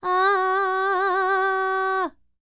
QAWALLI 10.wav